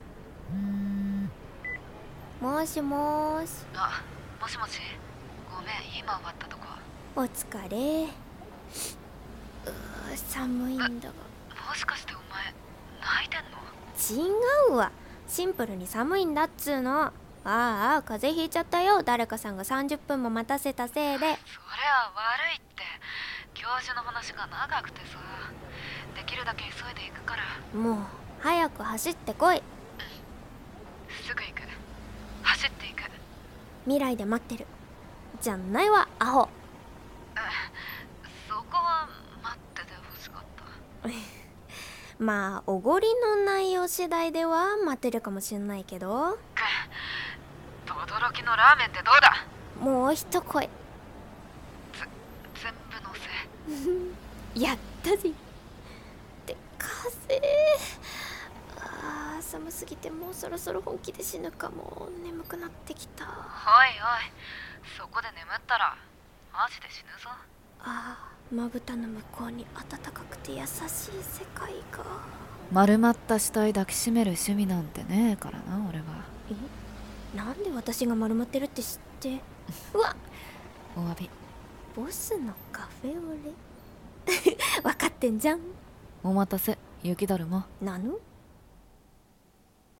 【声劇台本】カフェオレ